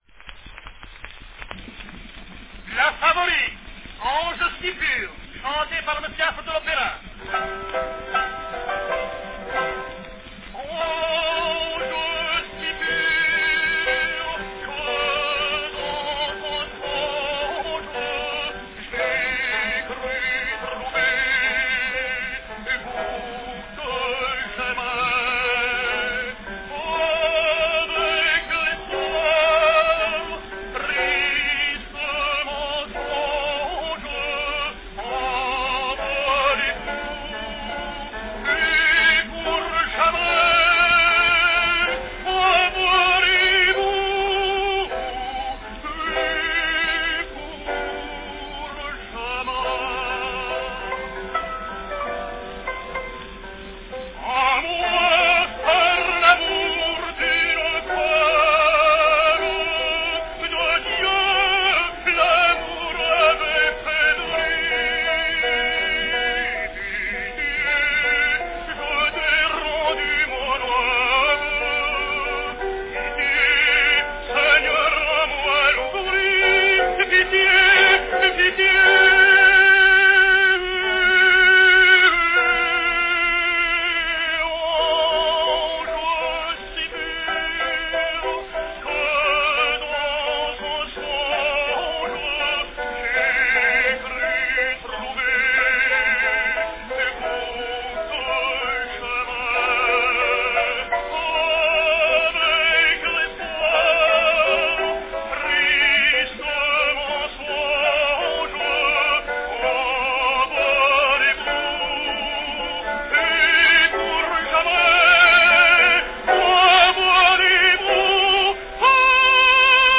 From 1903, the renowned French tenor Agustarello Affre sings La Favorite - "Ange si pur" on an uncommon 3½" 'salon' cylinder.
Category Tenor
Performed by Agustarello Affre
This recording, and others, announced by Affre typically sound so rushed (to my English ears) that he seems to pronounce his name "Aff".
The sound of the pantographic copying process employed by Pathé can be clearly heard rumbling along in the background throughout the recording.